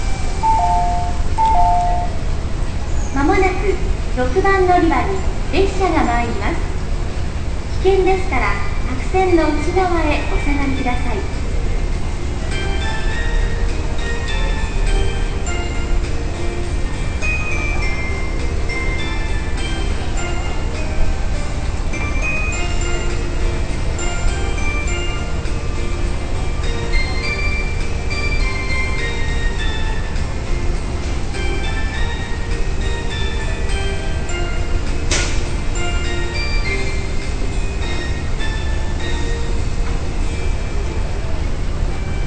駅構内で写真を撮り、主に岡山〜高松間の駅で使われている到着メロディ